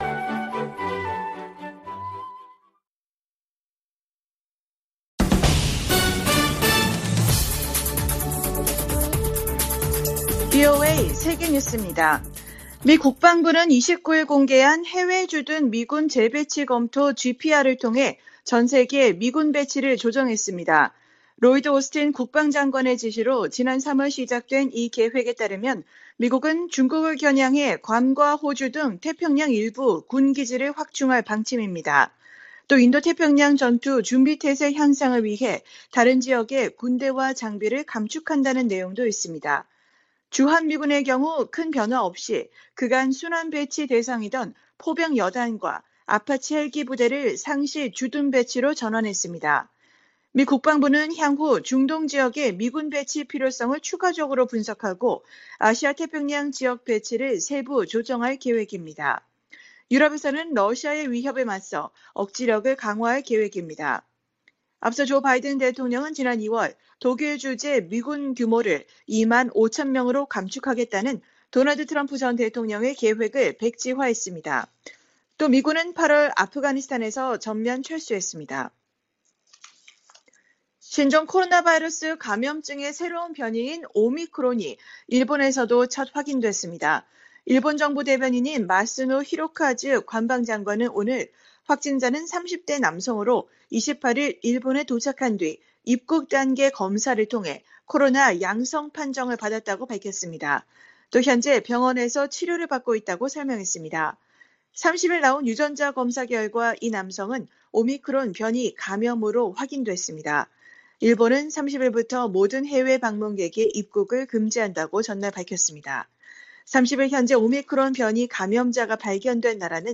VOA 한국어 간판 뉴스 프로그램 '뉴스 투데이', 2021년 11월 30일 2부 방송입니다. 해외주둔 미군 재배치 검토를 끝낸 미국방부는 주한미군 규모를 현행수준으로 유지하기로 했습니다. 북한 선박들의 공해상 움직임이 늘고 있는 가운데 미 국무부는 국제사회의 대북제재 이행의 중요성을 거듭 강조했습니다. 조 바이든 행정부 출범 이후 약 10개월 넘게 주한 미국대사가 공석인 가운데 적임자 발탁이 중요하다는 견해와 인선을 서둘러야 한다는 지적도 나오고 있습니다.